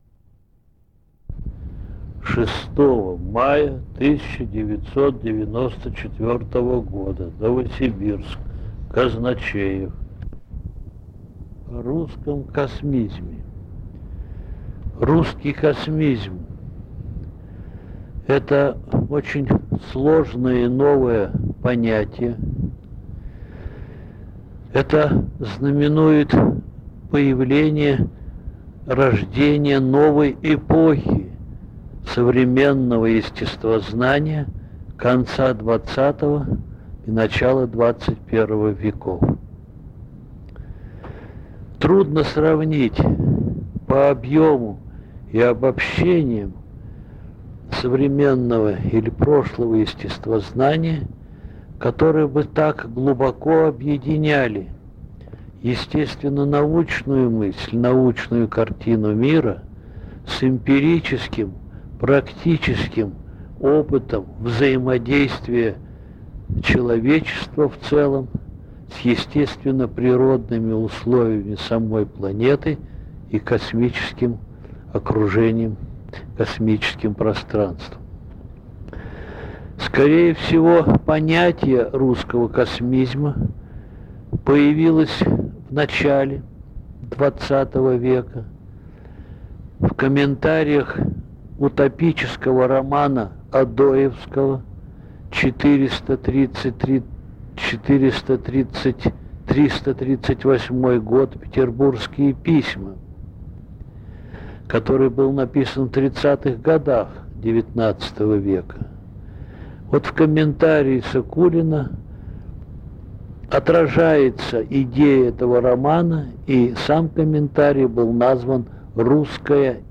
- Устная речь.